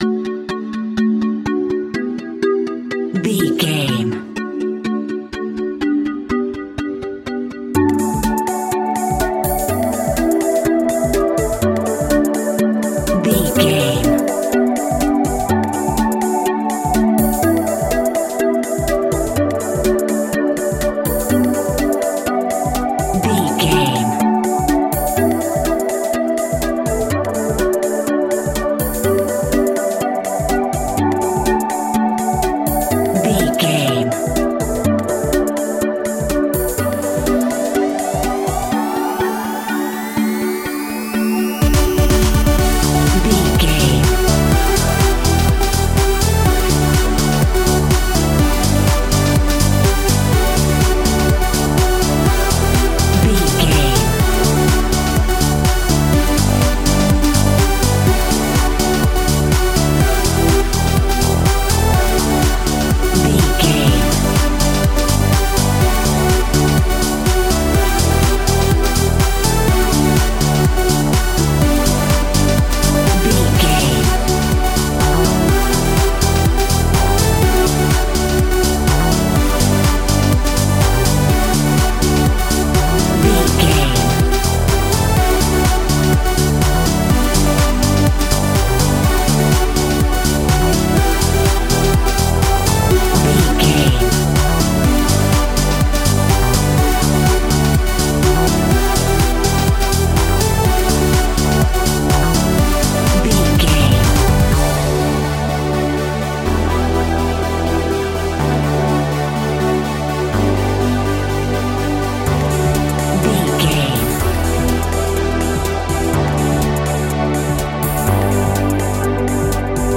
Ionian/Major
D
groovy
uplifting
futuristic
driving
energetic
repetitive
drum machine
synthesiser
techno
synth leads
synth bass